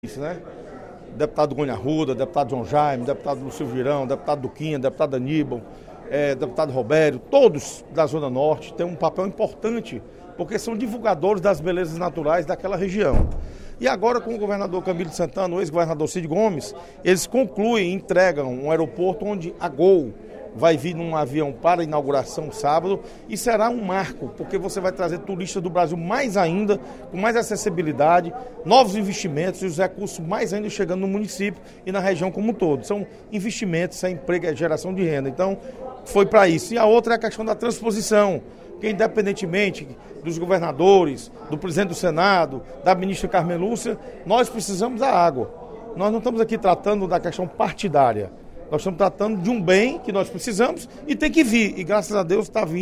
O deputado Osmar Baquit (PSD) destacou, durante o primeiro expediente da sessão plenária desta quinta-feira (22/06), a inauguração do Aeroporto de Jericoacoara, localizado no município de Cruz, que ocorrerá sábado (24/06).